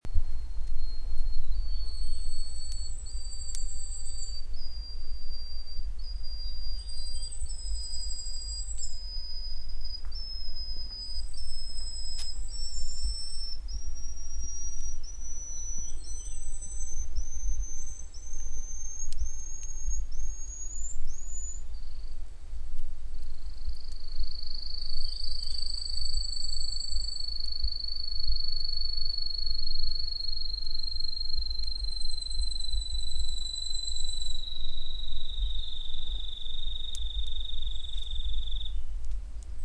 30-5塔塔加2012mar26深山鶯song1.mp3
黃腹樹鶯 Cettia acanthizoides concolor
南投縣 信義鄉 塔塔加
錄音環境 森林
鳥叫